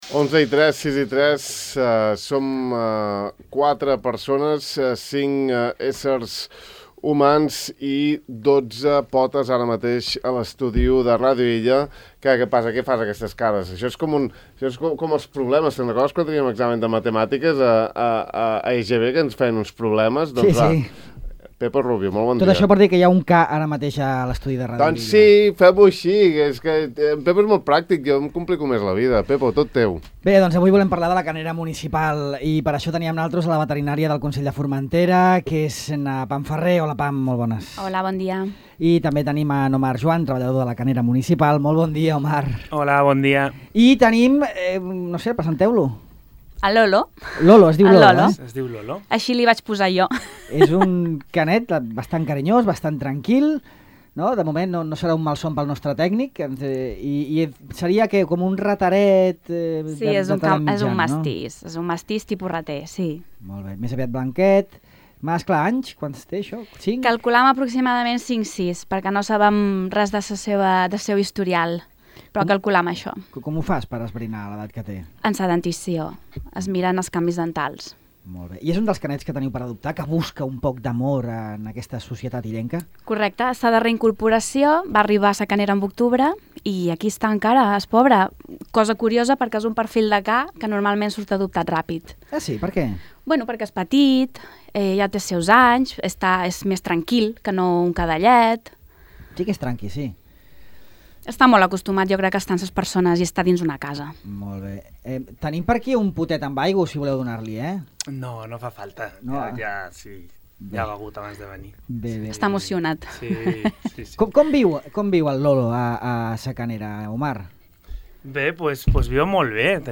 A més, han portat a l'estudi de Ràdio Illa un dels tres cans que viuen a la Canera i que esperen ser adoptats.